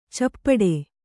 ♪ cappaḍe